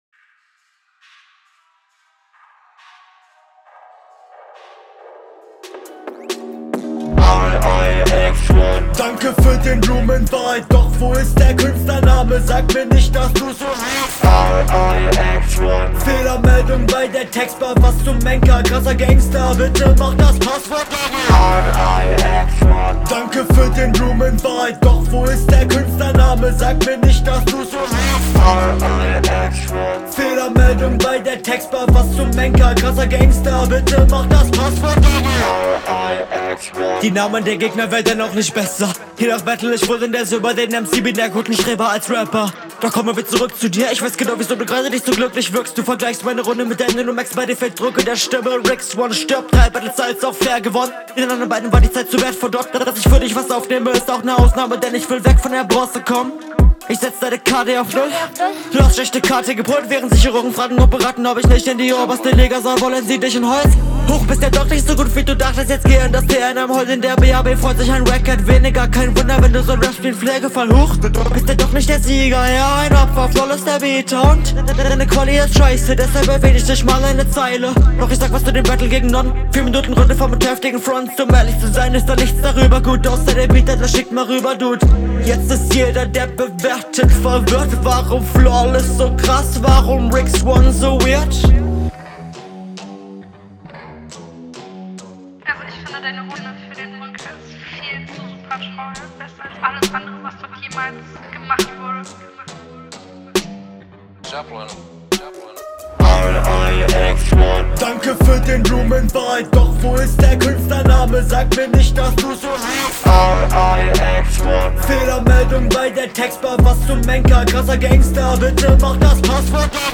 Bewertetes Battle
Hook kann man so machen.
konzept mit dem vocoder eig ganz cool, aber bisschen unverständlich hier und da. und dann …